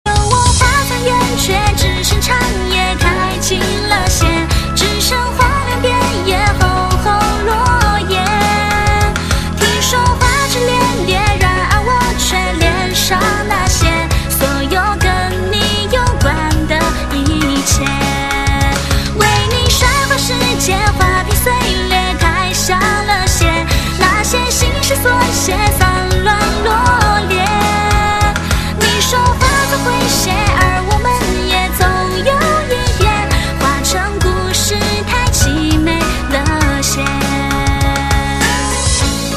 M4R铃声, MP3铃声, 华语歌曲 78 首发日期：2018-05-14 22:18 星期一